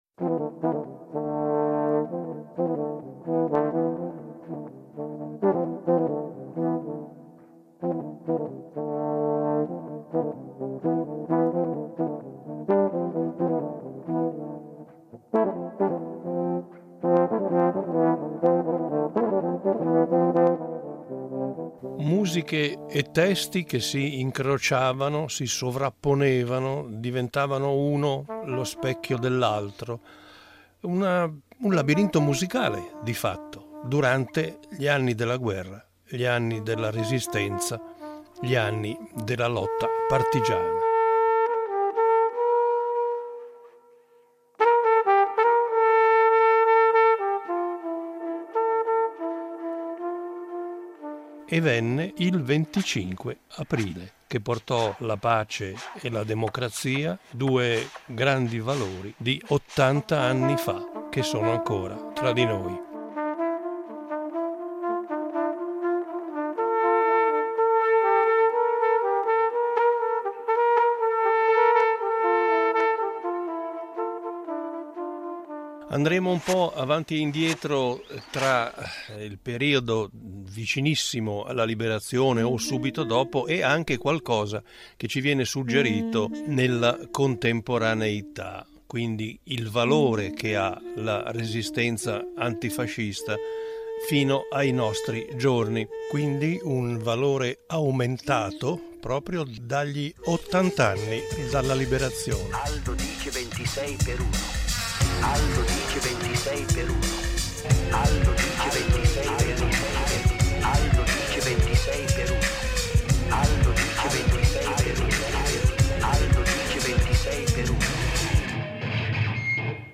A ridosso della Festa della Liberazione, Rete Due intraprende un viaggio radiofonico scandito in due puntate, attraverso le musiche della Resistenza antifascista italiana.